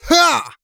XS普通5.wav 0:00.00 0:00.64 XS普通5.wav WAV · 55 KB · 單聲道 (1ch) 下载文件 本站所有音效均采用 CC0 授权 ，可免费用于商业与个人项目，无需署名。
人声采集素材